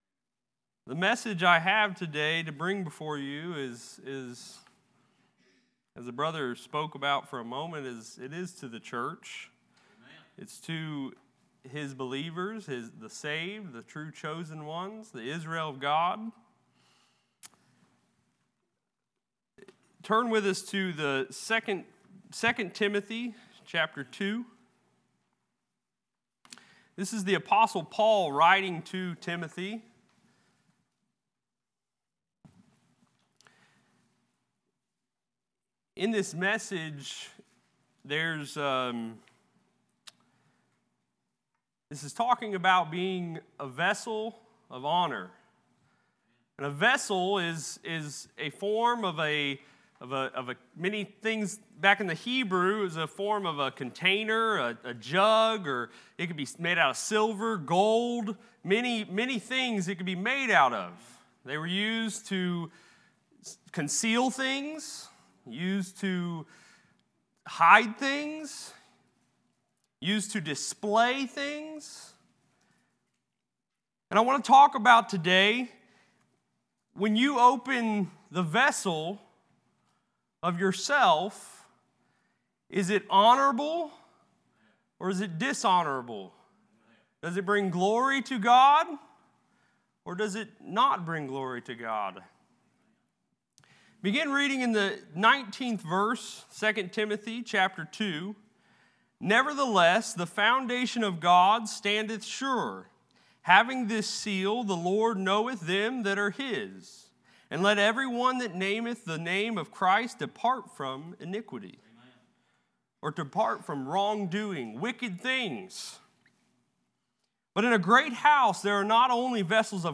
Series: Sunday Evening